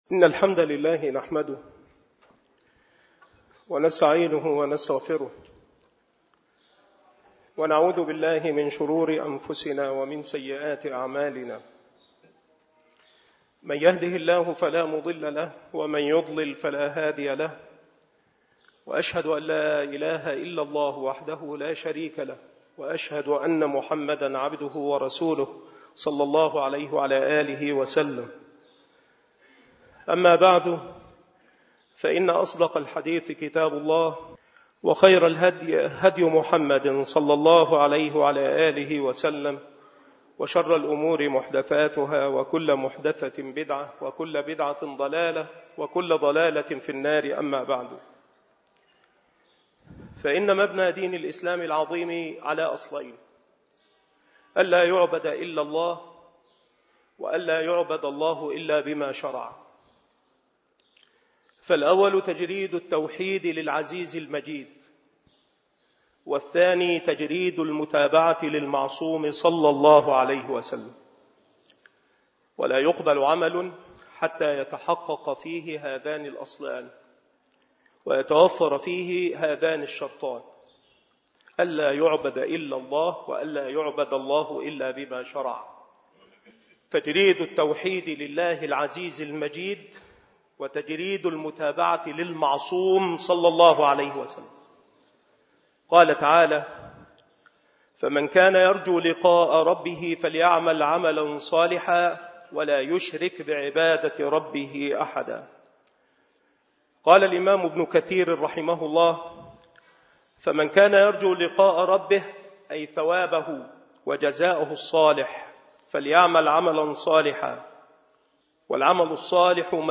محاضرة
بمسجد صلاح الدين بمدينة أشمون - محافظة المنوفية